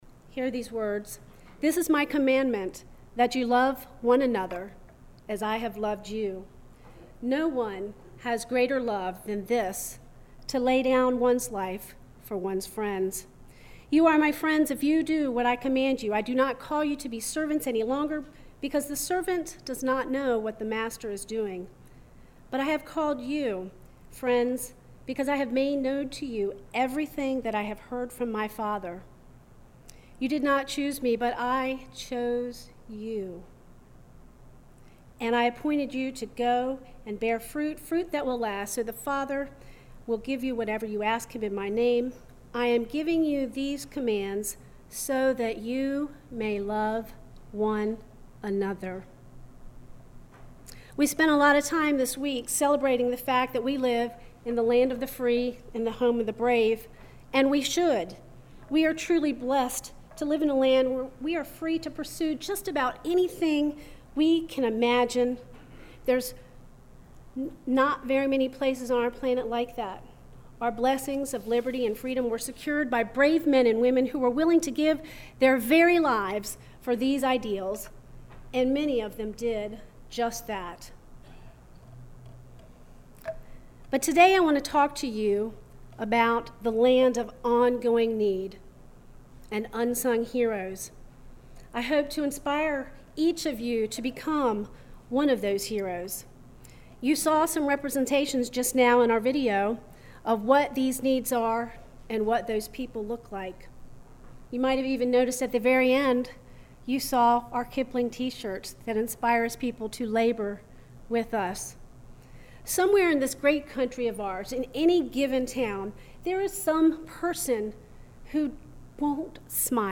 This weeks scripture and sermon:
7-7-19-scripture-and-sermon-1.mp3